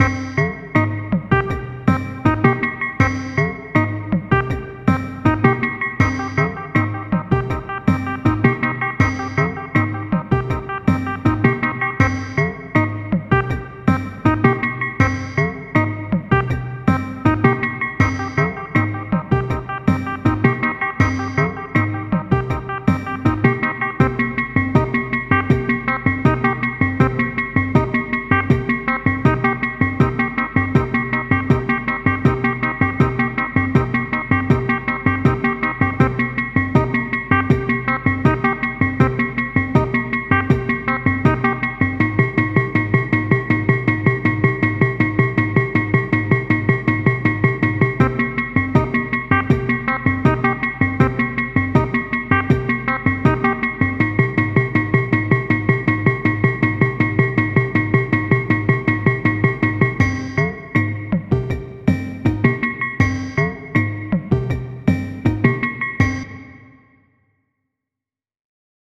Pieza Intelligent dance music (IDM)
Dance
melodía
sintetizador